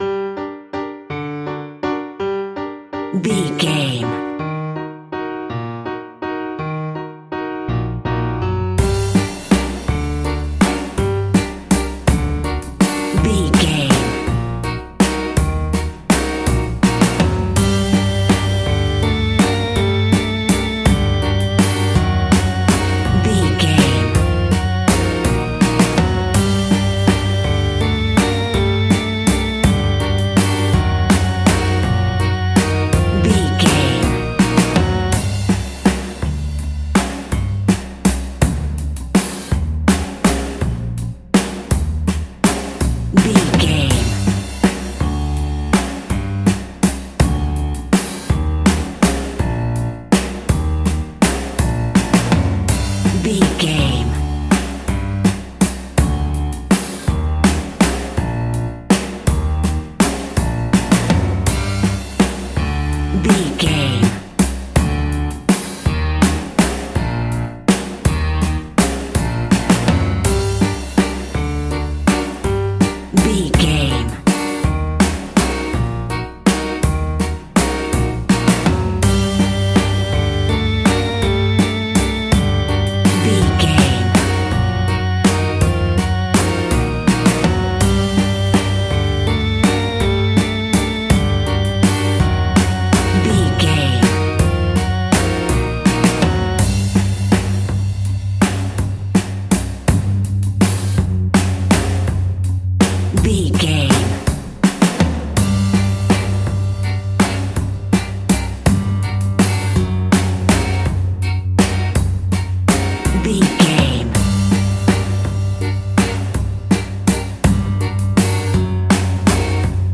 Aeolian/Minor
ominous
eerie
piano
synthesiser
drums
electric organ
strings
spooky
horror music